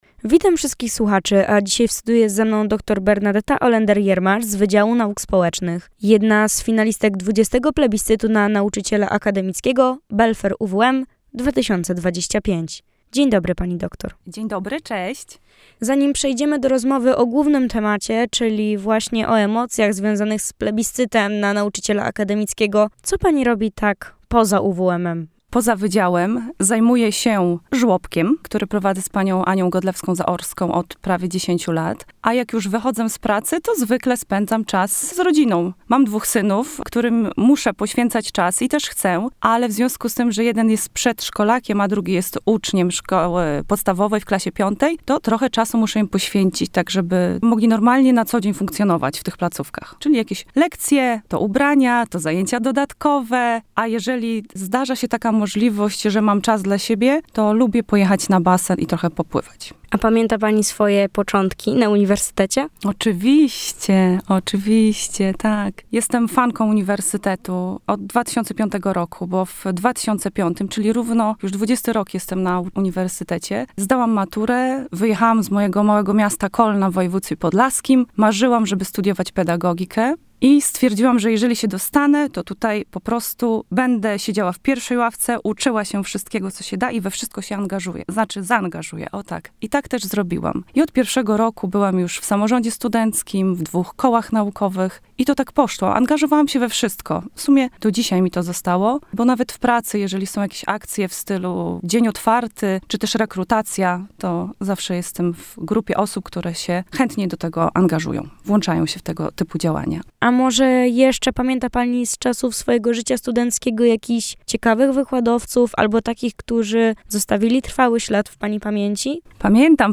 – przyznała w naszym radiowym studiu.